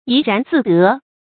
yí rán zì dé
怡然自得发音
成语注音ㄧˊ ㄖㄢˊ ㄗㄧˋ ㄉㄜˊ
成语正音得，不能读作“děi”；怡，不能读作“tái”。